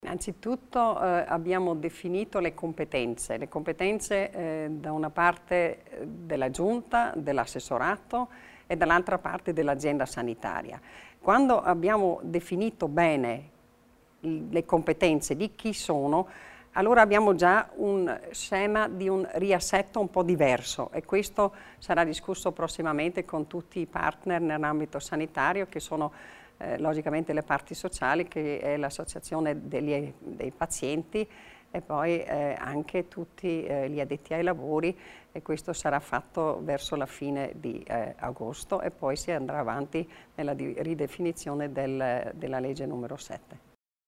L'Assessore Martha Stocker spiega i nuovi passi della riforma sanitaria